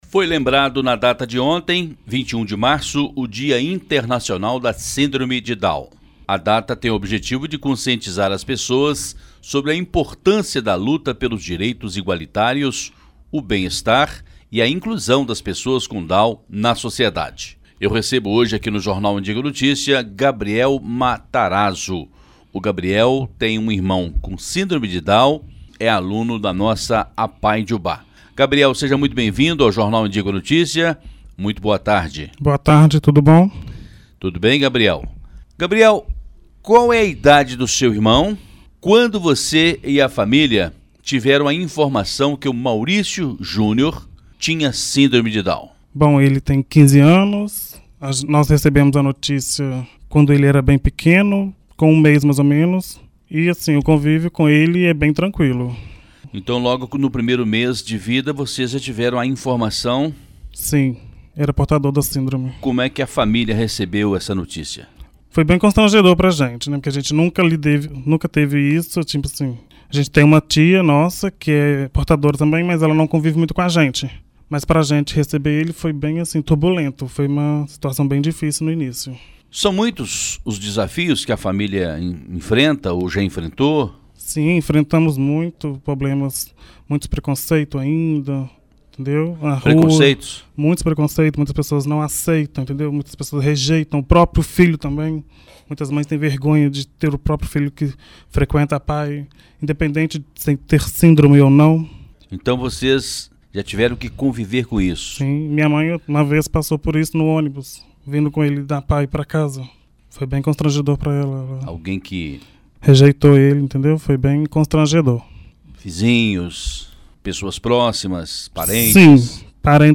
Entrevista exibida na Rádio Educadora AM/FM Ubá – MG